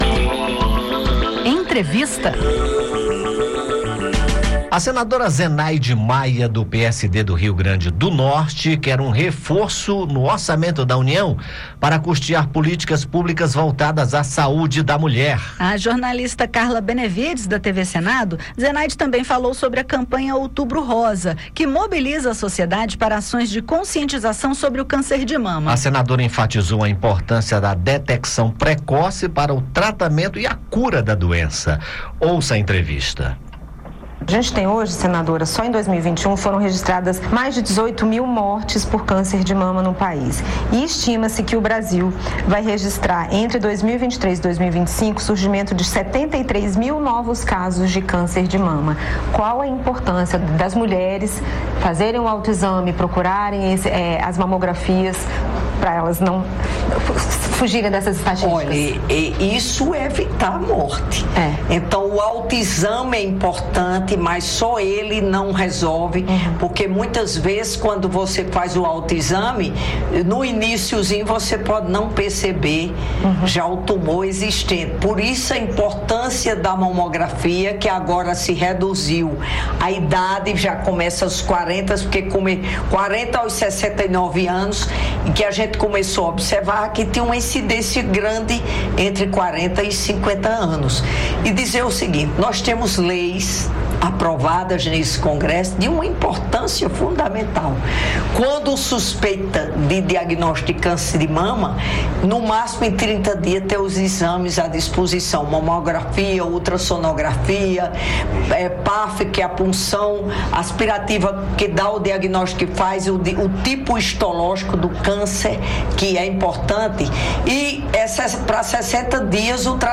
conversou com a senadora sobre o assunto. Zenaide enfatizou a importância da detecção precoce do câncer para o tratamento e cura da doença, as propostas para melhoria dos atendimentos pelo SUS e falou da importância da campanha Outubro Rosa, que mobiliza a sociedade para ações de conscientização sobre o câncer de mama.